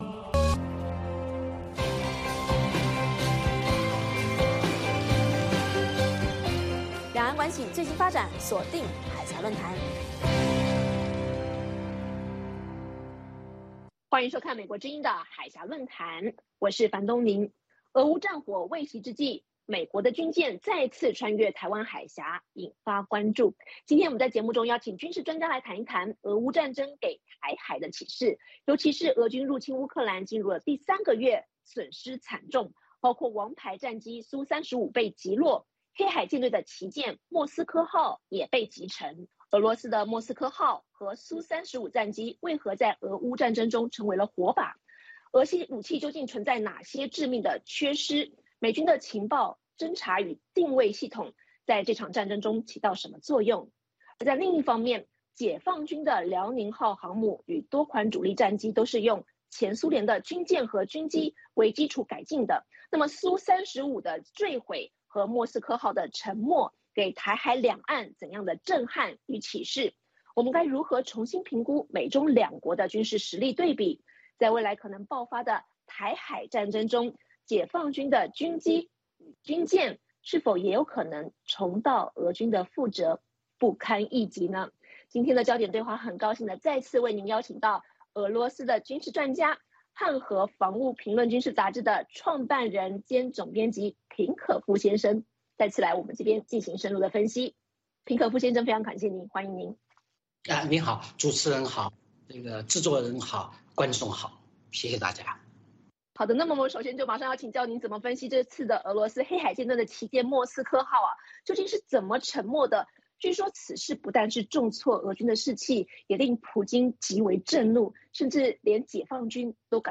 今天我们邀请俄罗斯军事专家来谈谈俄乌战争给台海战争的启示。